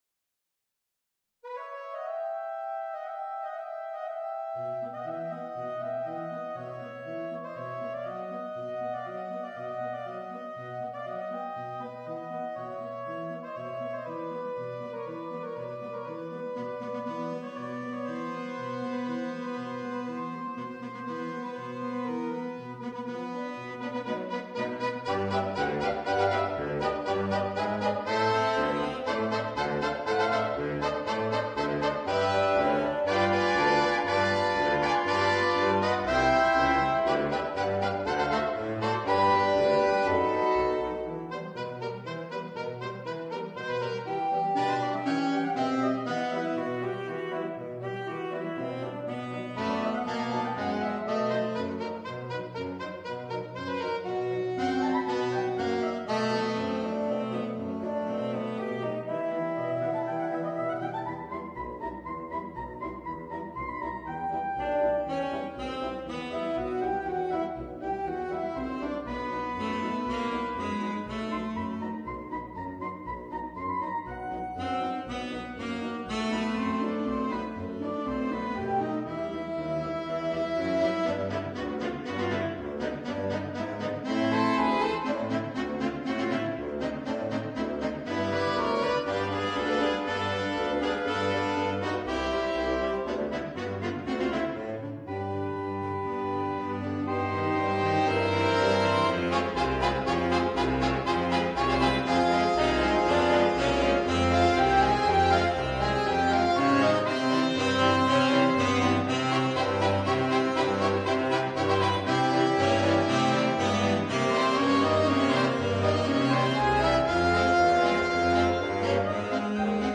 Per sette saxofoni
CORO DI SAXOFONI